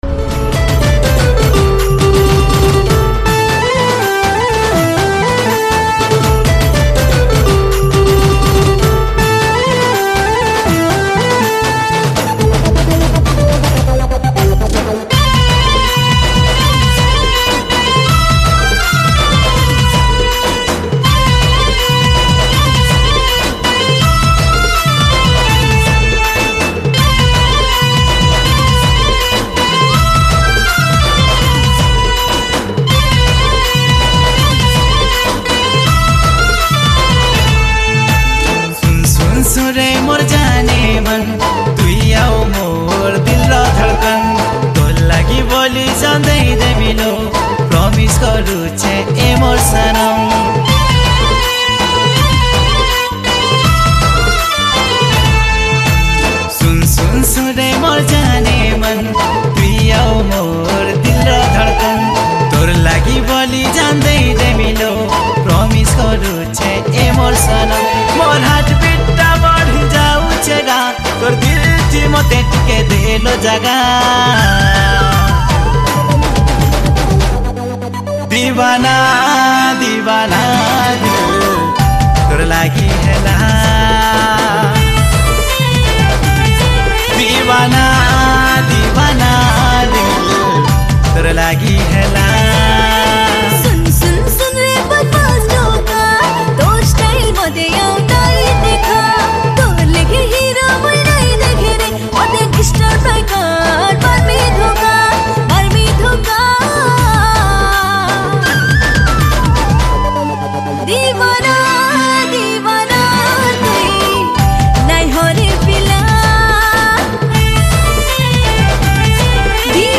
Sambalpuri Romantic Song